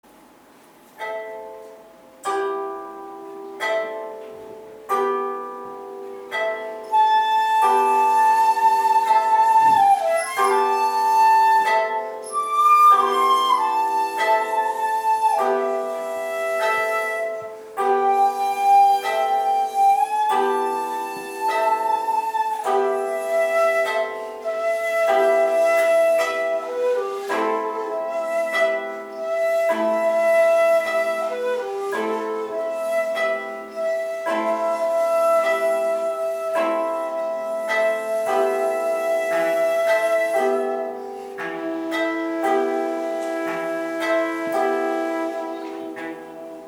尺八と琴が一緒に演奏される練習番号（５）では琴の二分音符でAm-Emの繰り返しで進行していきます。
尺八はお琴の二分音符に乗っていくようにしてメロディーを歌って下さい。